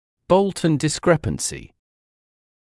[‘bəultn dɪs’krepənsɪ][‘боултн дис’крэпэнси]несоответствия размера нижних зубов верхним по методике Болтона